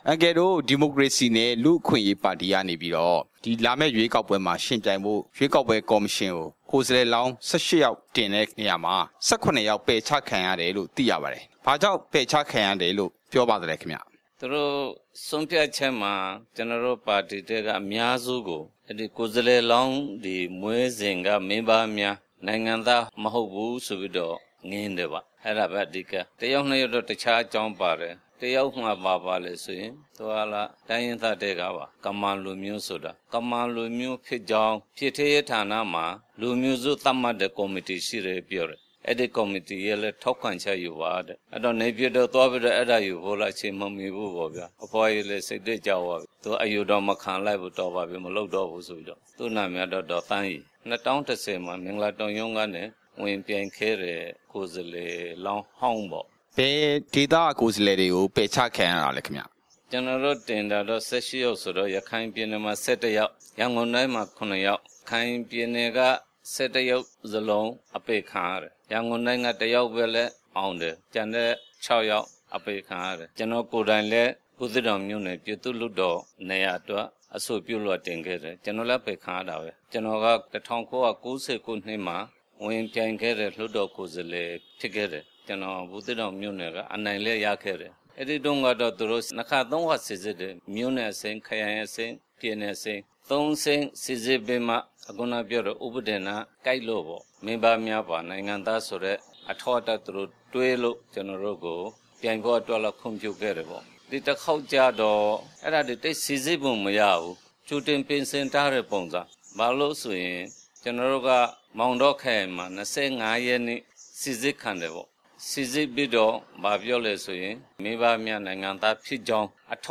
ဒီမိုကရေစီနှင့်လူ့အခွင့်အရေးပါတီ အခြေအနေ မေးမြန်းချက်